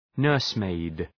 Προφορά
{‘nɜ:rsmeıd}
nursemaid.mp3